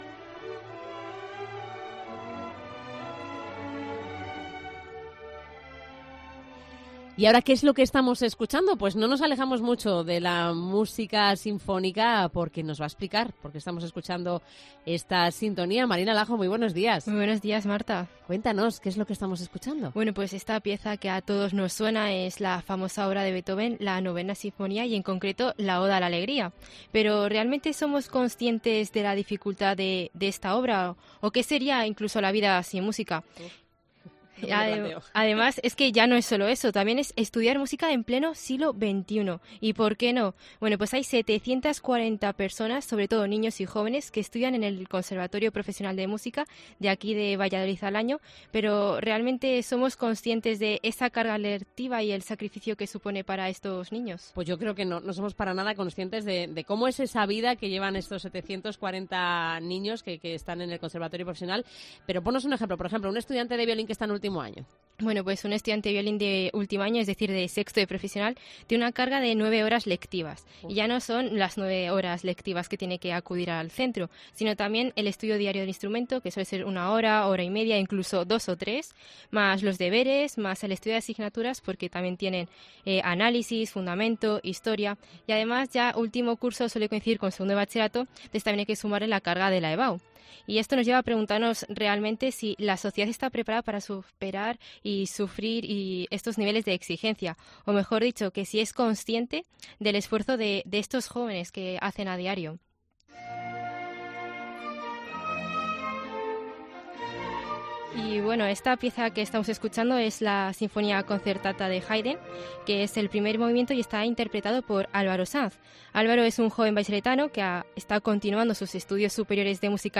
Reportaje sobre los estudios musicales en el Conservatorio Profesional de Música de Valladolid